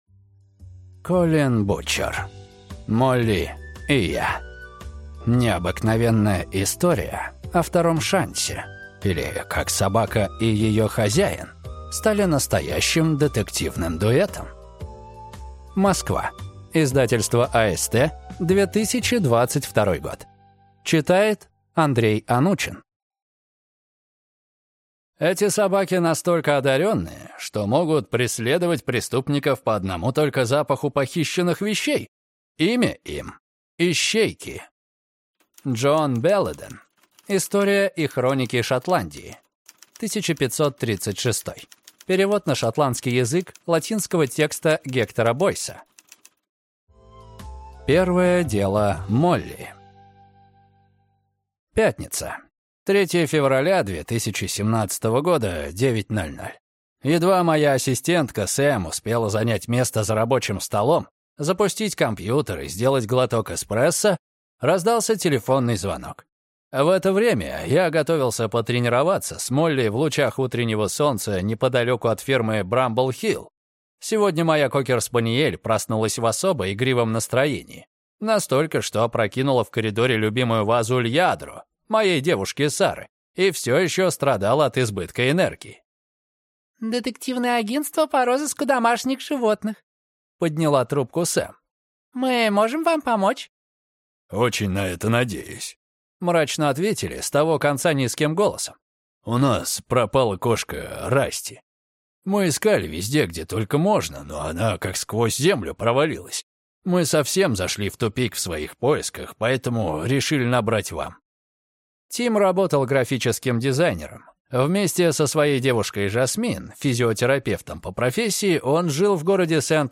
Аудиокнига Молли и я. Невероятная история о втором шансе, или Как собака и ее хозяин стали настоящим детективным дуэтом | Библиотека аудиокниг